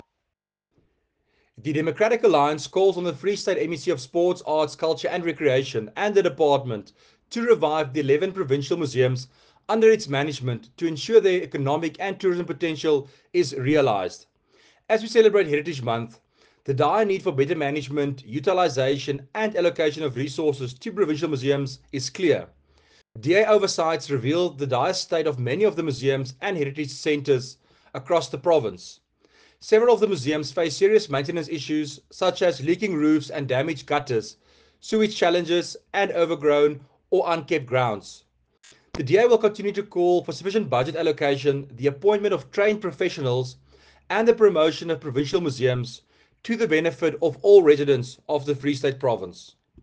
Afrikaans soundbites by Werner Pretorius MPL, and